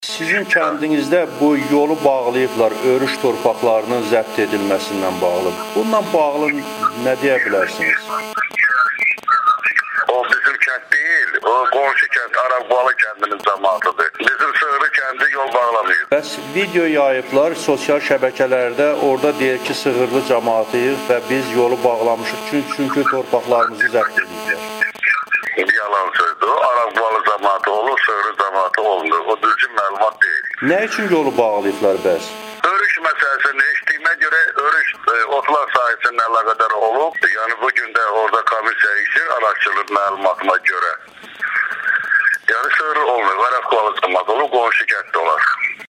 Sığırlı kənd bələdiyyəsinin sədri Saleh Ağamalıyevin Amerikanın Səsinə müsahibəsi